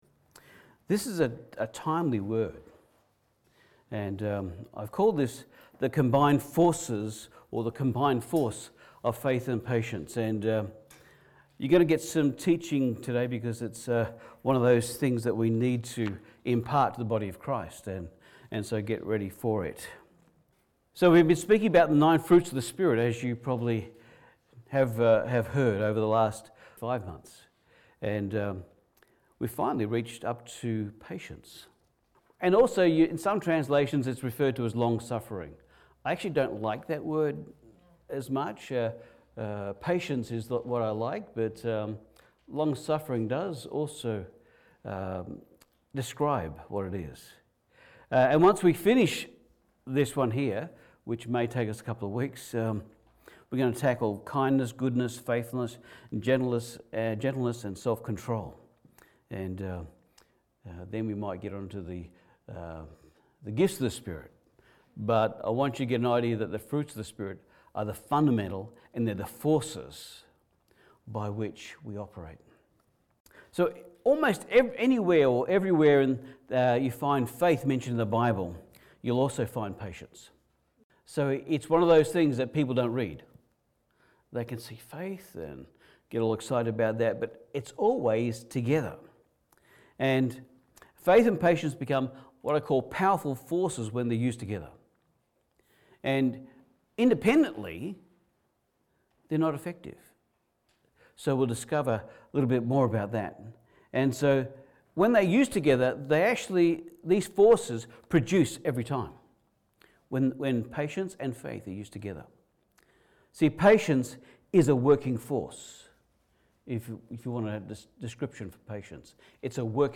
Hebrew 6:12 Service Type: Sunday Service Almost everywhere you find faith mentioned in the Bible